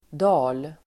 Uttal: [da:l]